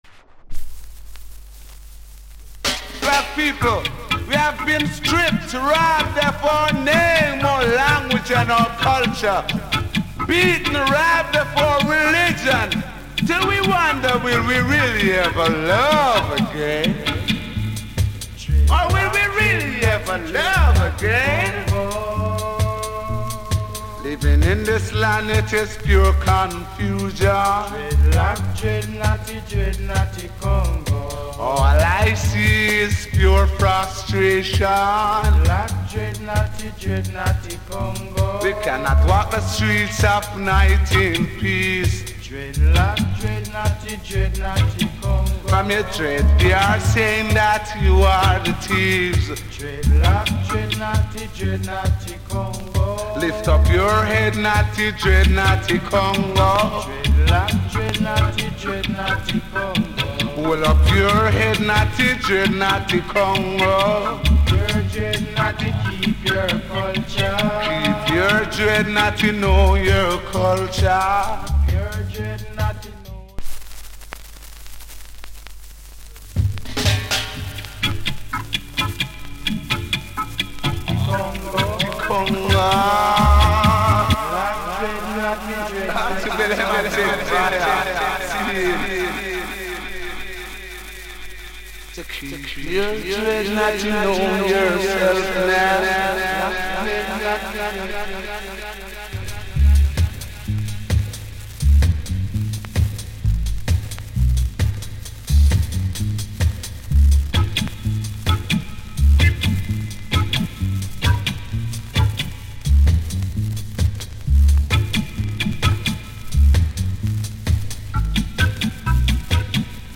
同オケ、重怖。